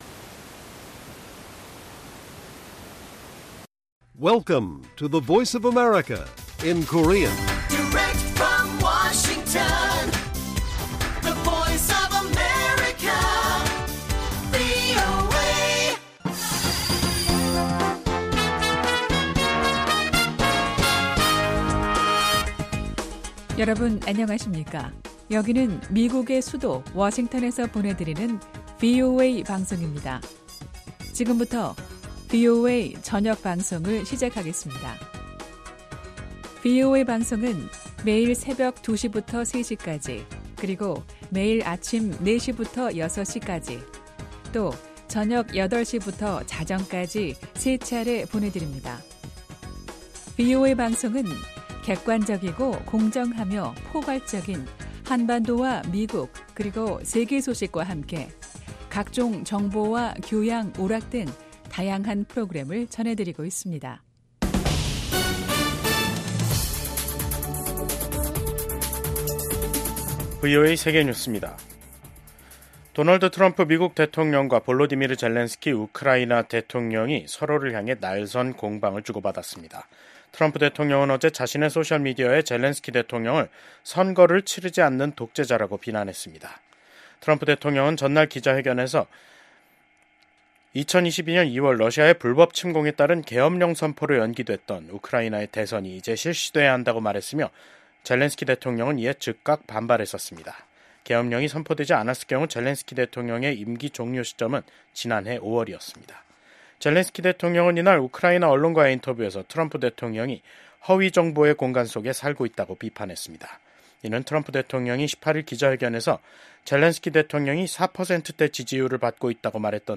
VOA 한국어 간판 뉴스 프로그램 '뉴스 투데이', 2025년 2월 20일 1부 방송입니다. 미국 정부는 중국이 타이완 해협의 평화와 안정을 해치고 있다는 점을 지적하며 일방적 현상 변경에 반대한다는 입장을 확인했습니다. 미국의 ‘핵무기 3축’은 미국 본토에 대한 북한의 대륙간탄도미사일 공격을 효과적으로 억지할 수 있다고 미국 공군 소장이 말했습니다.